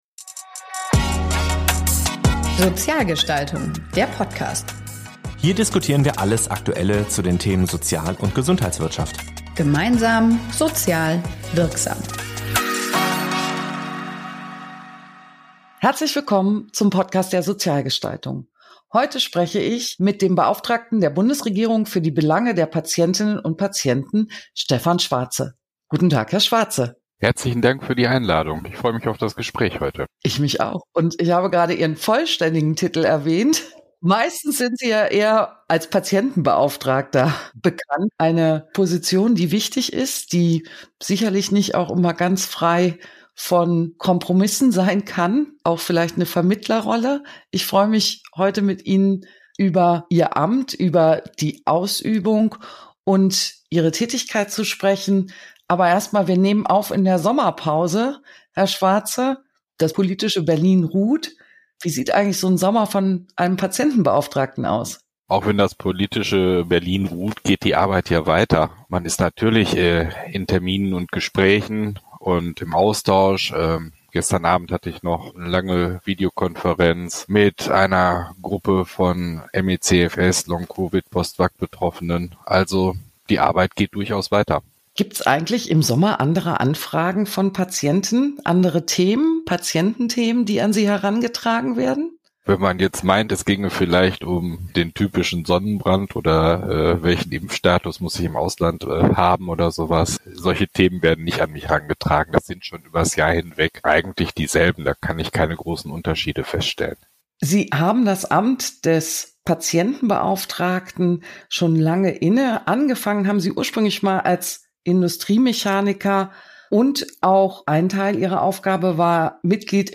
zu einem intensiven Gespräch rund um die Herausforderungen und Chancen unseres Gesundheitssystems.